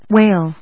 /(h)wéɪl(米国英語), wéɪl(英国英語)/